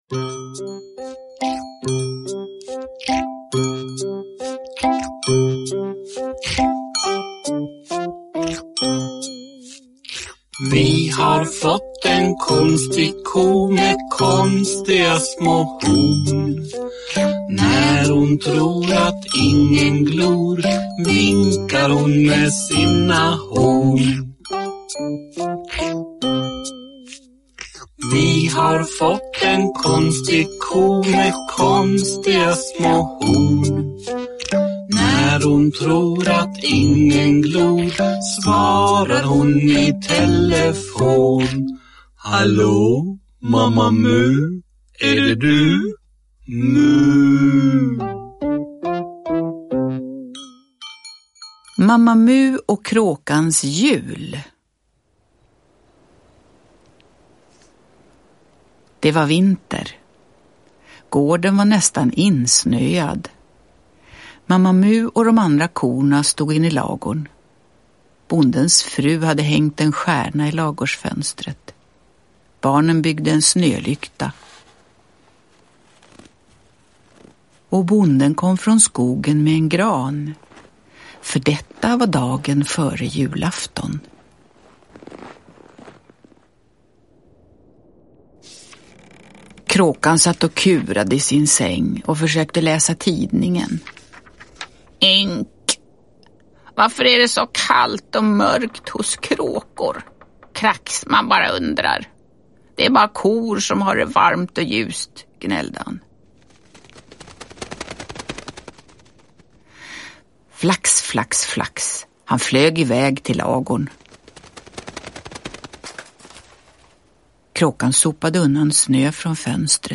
Uppläsning med musik.
Uppläsare: Jujja Wieslander